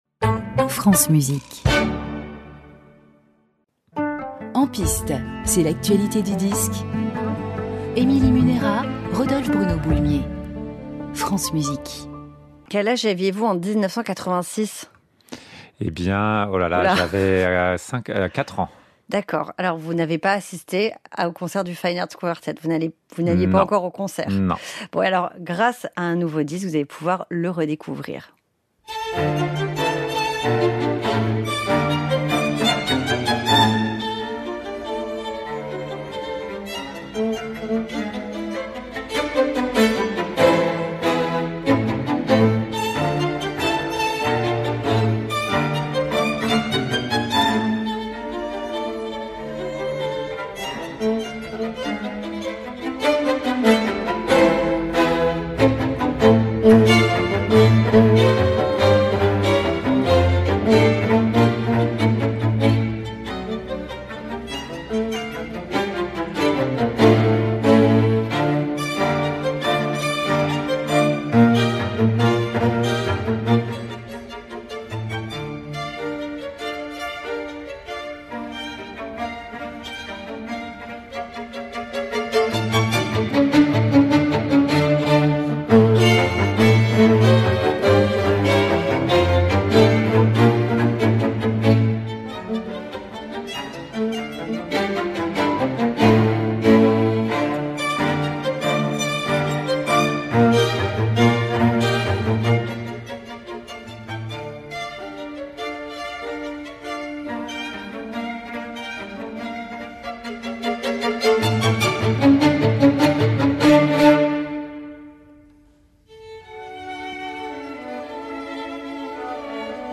Quatuor à cordes n°14 en ré min D 810
Quatuor à cordes n°19 en Ut Maj K 465